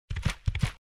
move02.mp3